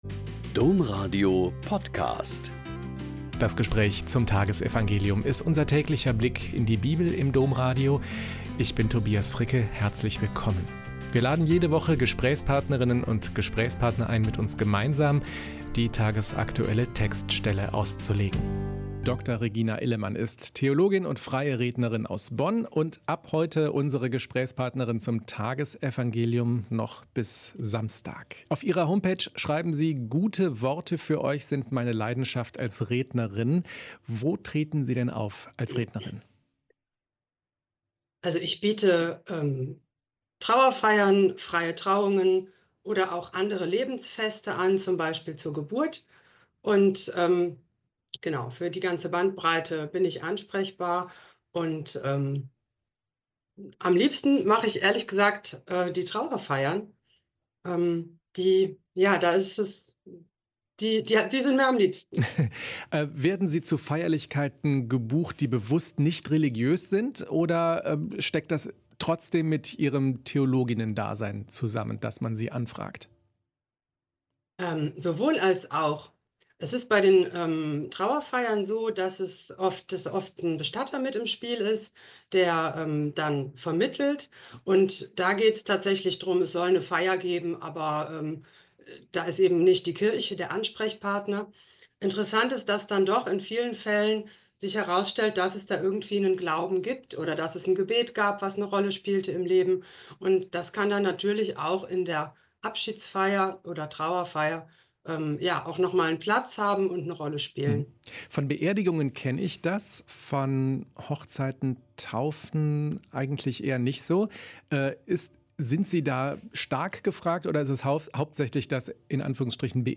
Mt 5, 43-48 - Gespräch